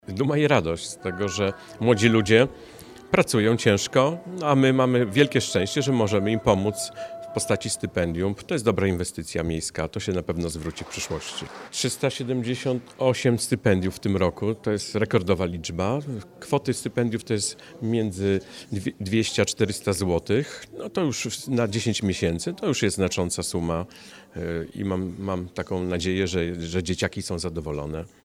– Jesteśmy dumni z młodych talentów – mówi Ryszard Kessler, wiceprezydent Wrocławia.